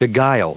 added pronounciation and merriam webster audio
512_beguile.ogg